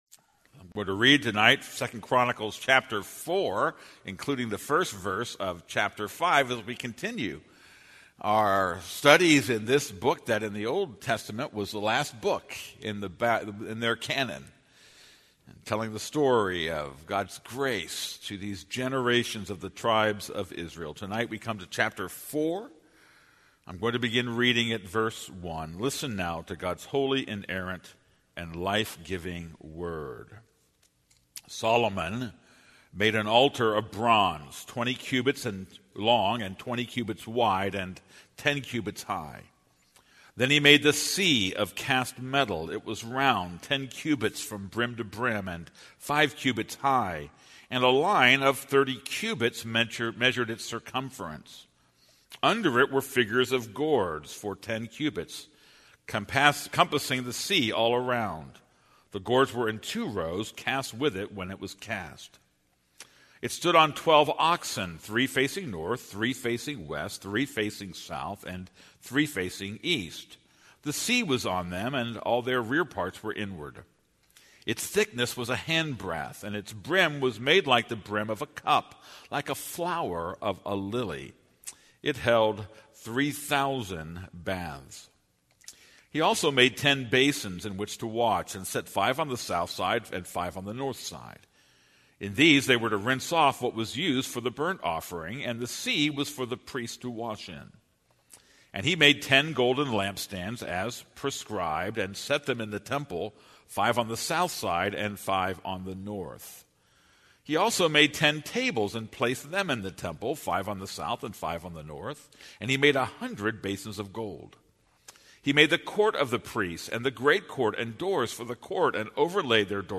This is a sermon on 2 Chronicles 4:1-5:1.